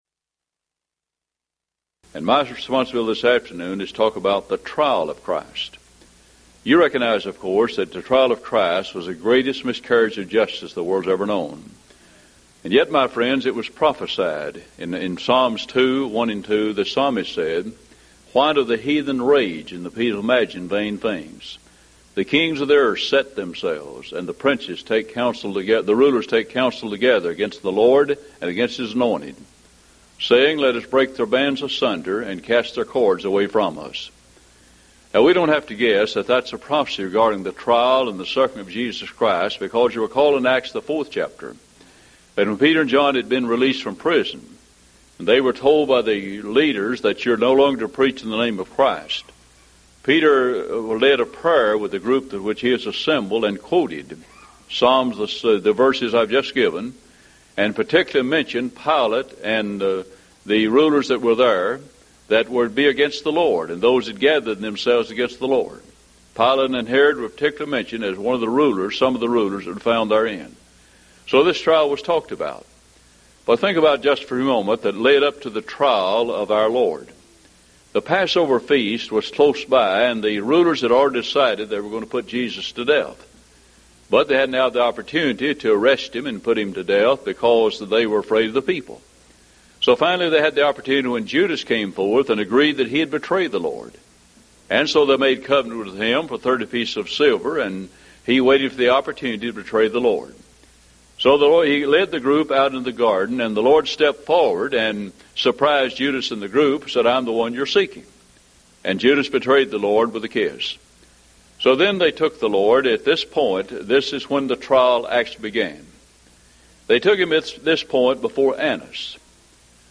Event: 1998 Mid-West Lectures
lecture